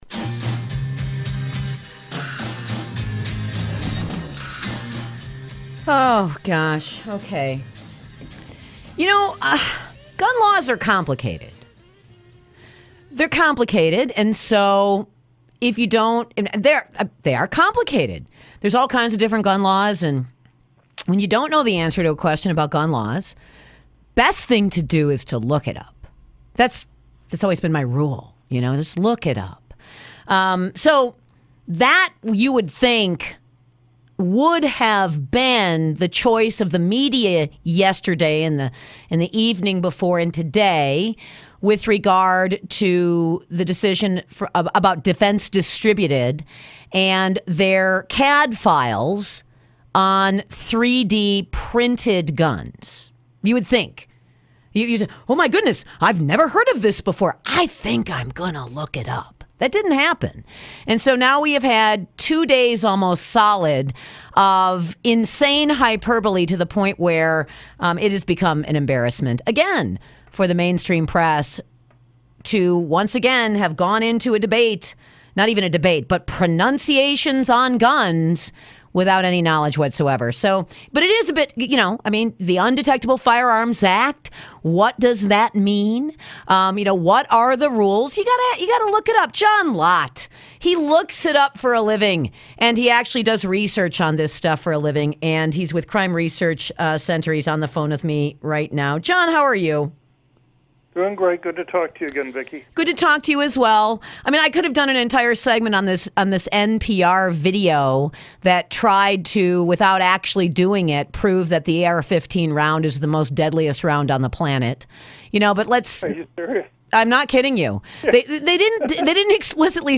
On Milwaukee's Giant 50,000-watt WISN to discuss 3D printed guns - Crime Prevention Research Center